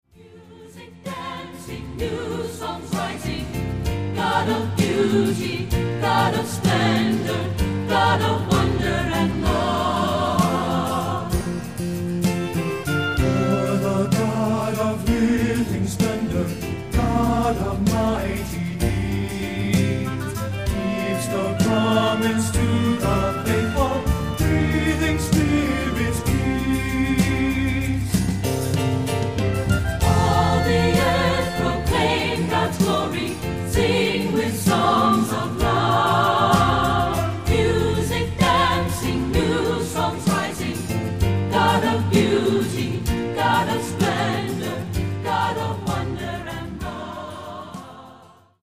Accompaniment:      Keyboard, C Instrument I;C Instrument II
Music Category:      Christian